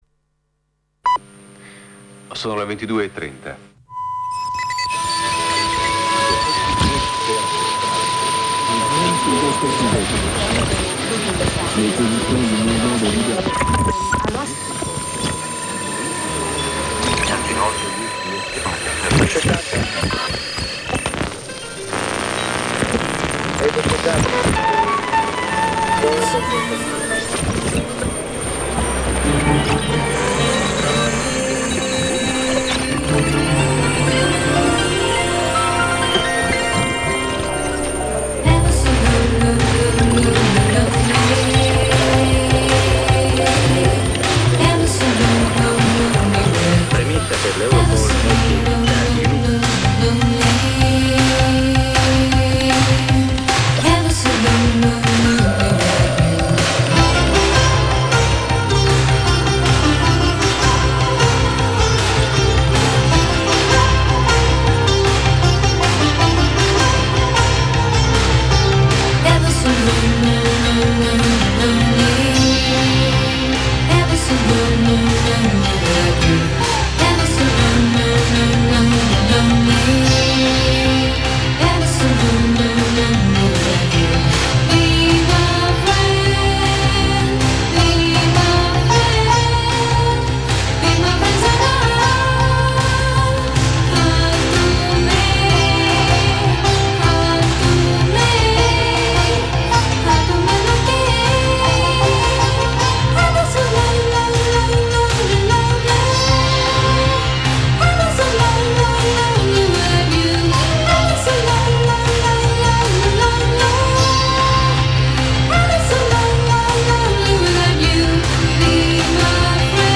Une interview en français : un livre illustré sur l’anatomie du clitoris, avec un grand nombre d’illustrations inédites et détaillées et des schémas en couleurs.